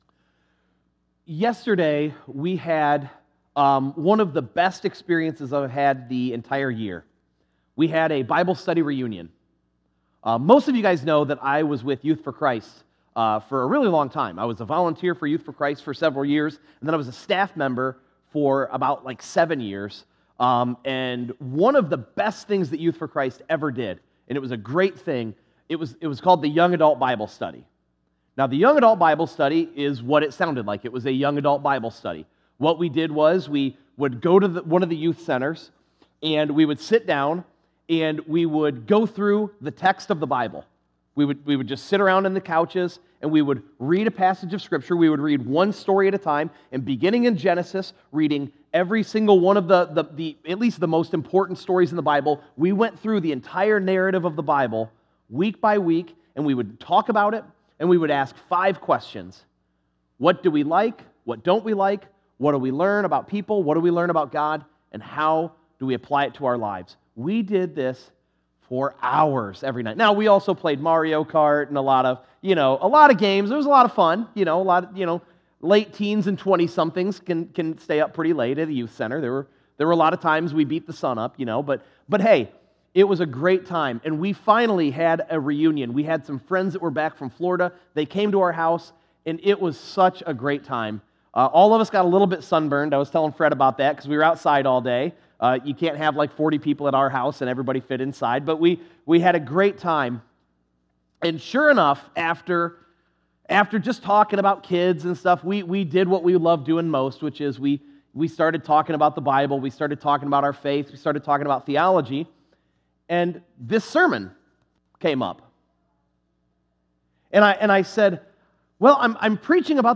Sermons - First Brethren Church- Bryan Ohio
7_7_24_sunday_sermon.mp3